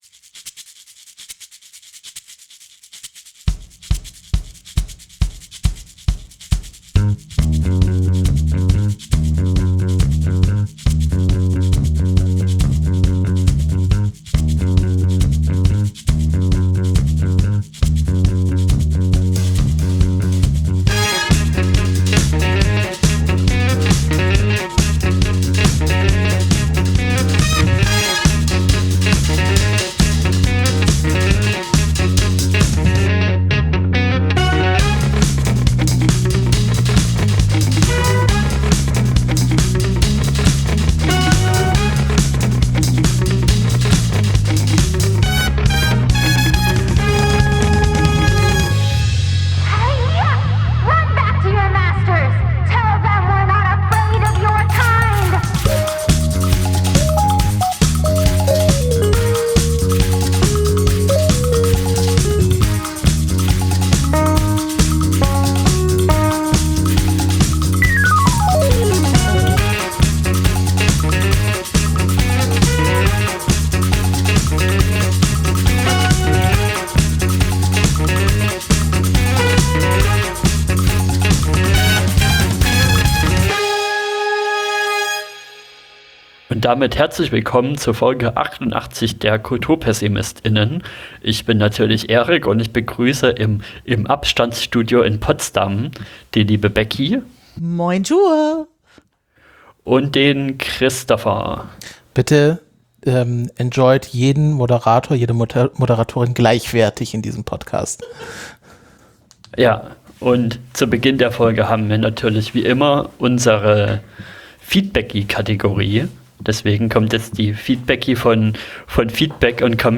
Einmal pro Monat kommen wir (die eigentlich an ganz unterschiedlichen Orten in Deutschland leben) in unserem virtuellen Aufnahmestudio zusammen, um über aktuelle Serien und Filme zu sprechen – denn dafür brennen wir. Vom heiß erwarteten Blockbuster bis zum Miniserien-Geheimtipp kommen bei uns ganz unterschiedliche Formate auf den Tisch.